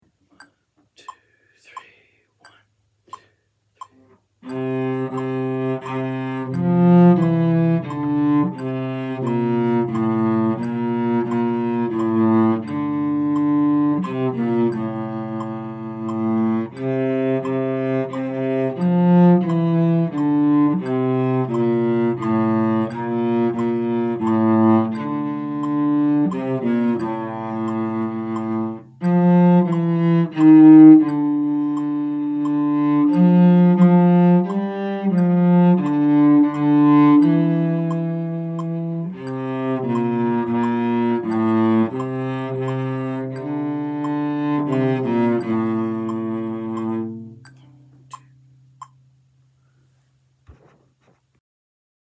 Chorale No 12, Alto